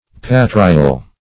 Patrial \Pa"tri*al\ (p[=a]"tr[i^]*al), a. [L. patria fatherland,